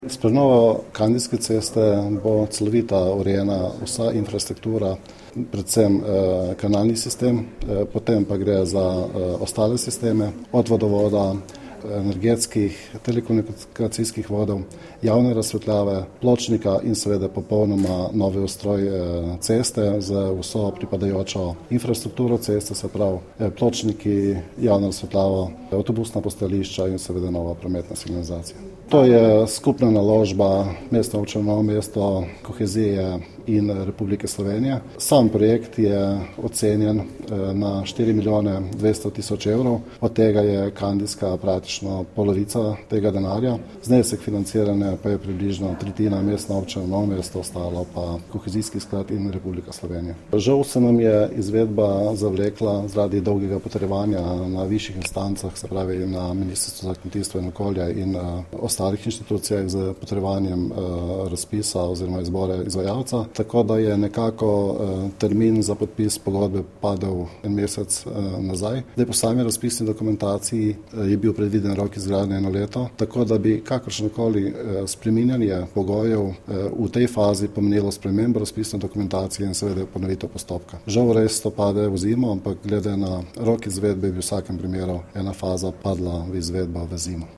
Župan Alojzij Muhič o pomenu projekta za Novo mesto